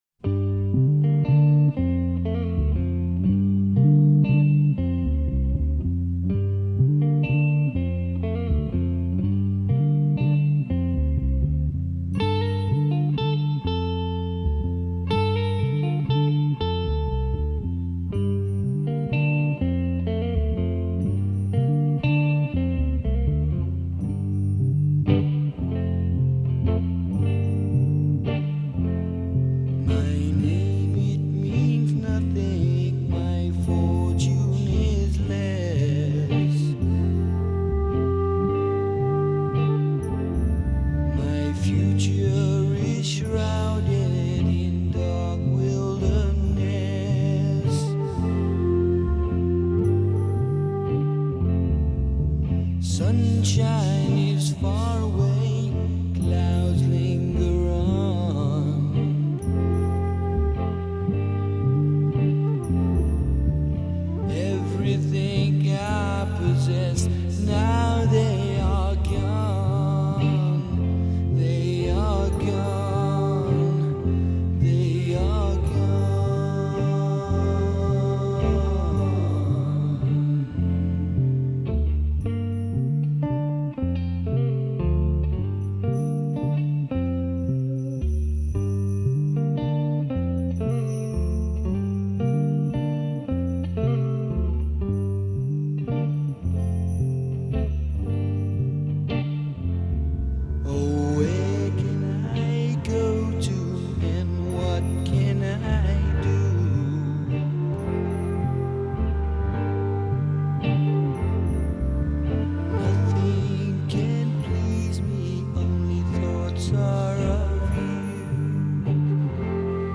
heavy metal
شاهکاری مملو از ریف های زیبا و تکنیک های جذاب